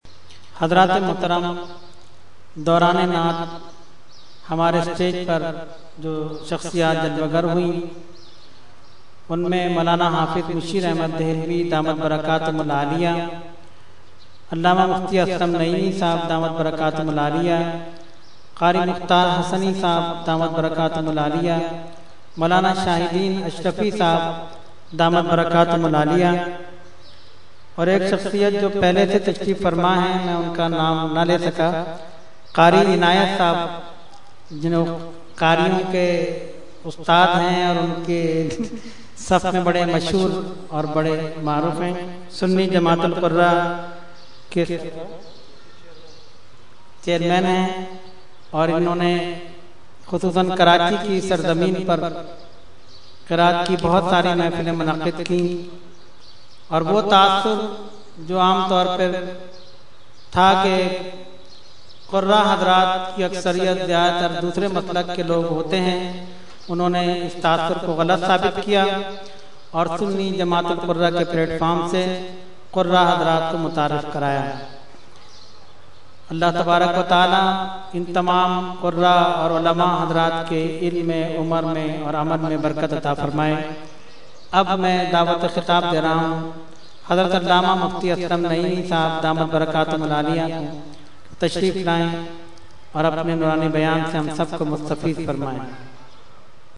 Short Speech – Urs Qutbe Rabbani 2012 – Dargah Alia Ashrafia Karachi Pakistan